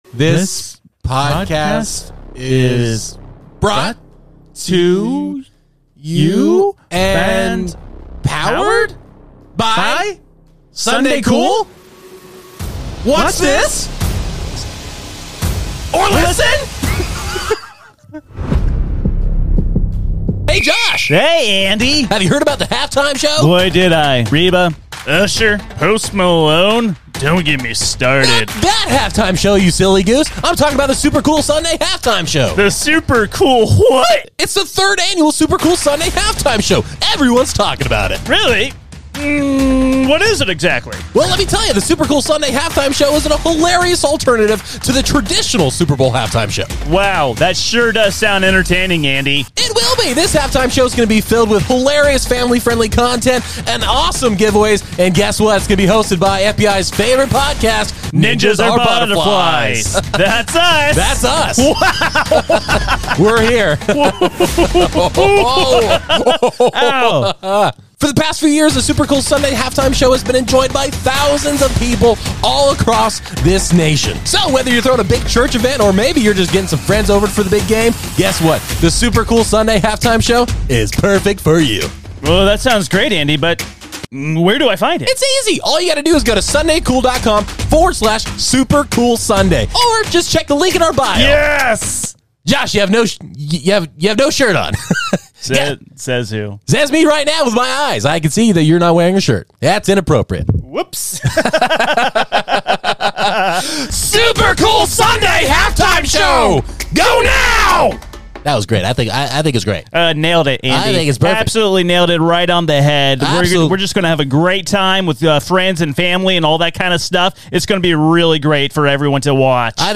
Todays episode of Ninjas are Butterflies is packed full of conspiracies, debates, and big laughs. The crew does everything they can to cover the topics they know will have you cracking up and rolling with anxiety.